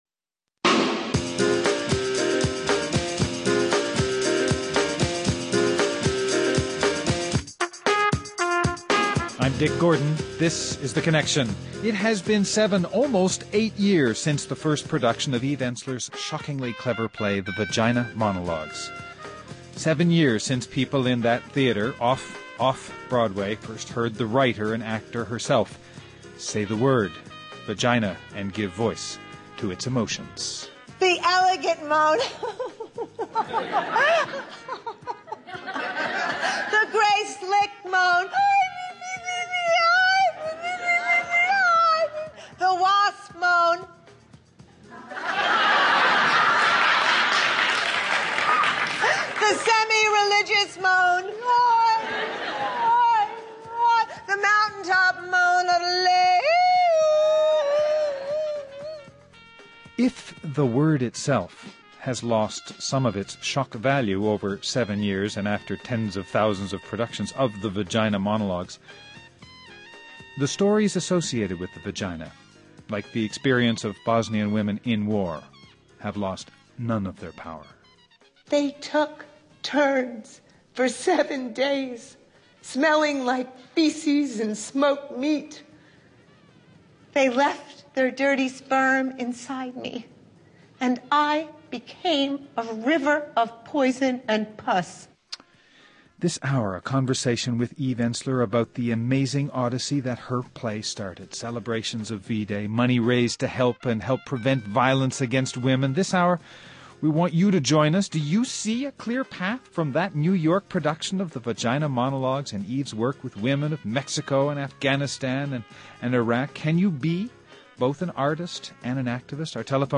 This hour we’re talking with Eve Ensler about being both an artist and an activist.
Guests: Eve Ensler, playright, activist